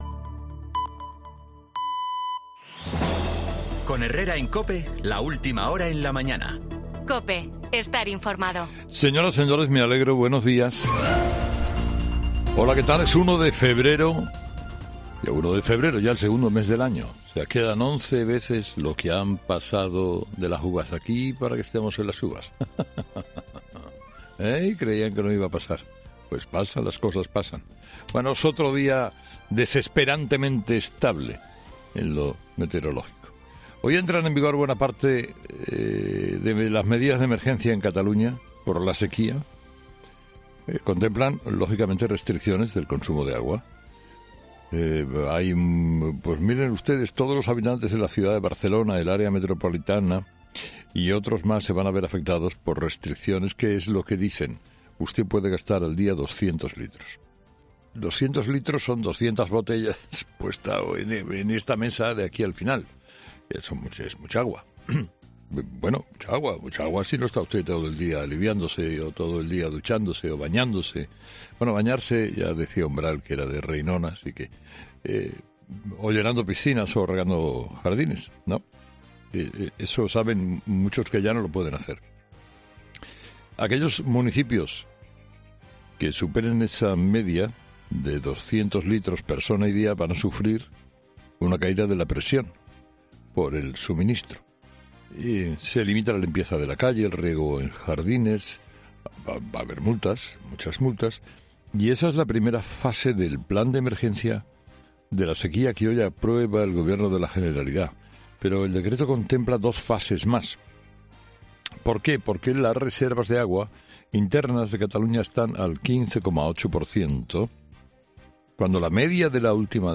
Escucha el monólogo de Herrera del jueves 1 de febrero de 2024